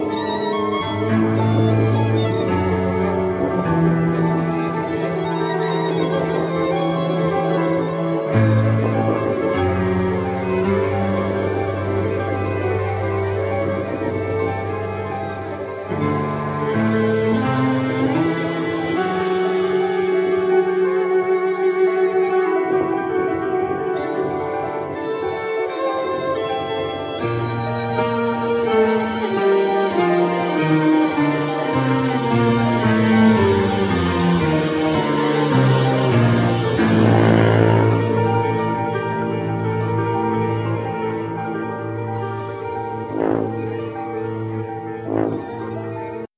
Piano
Violin
Cello
Tuba
Percussins